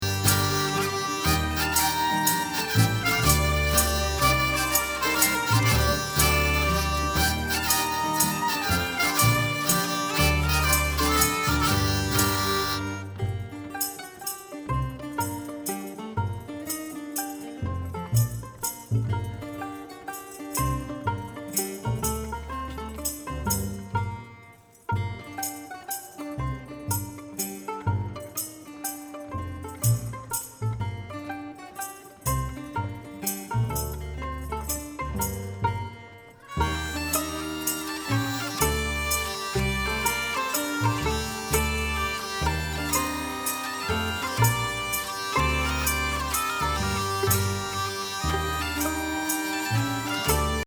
live
Improvisation Trommel u. Flöte